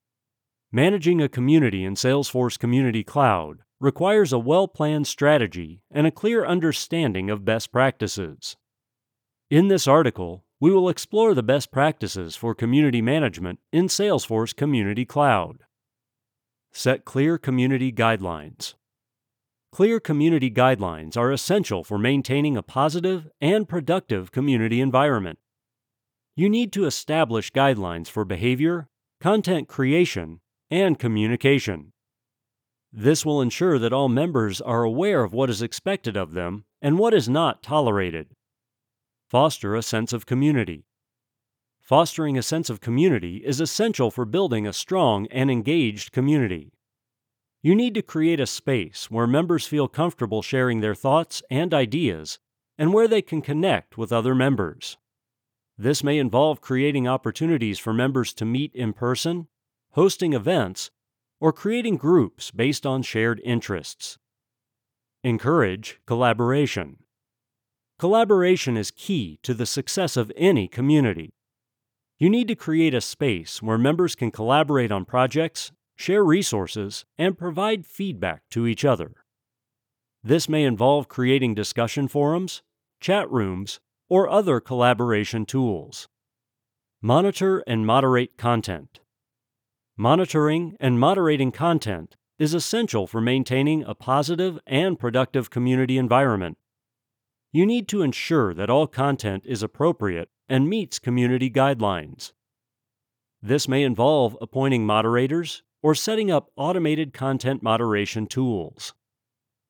New Audiobook Available
I recently had the opportunity to provide the voice for the audiobook version of Learn Salesforce Community Cloud.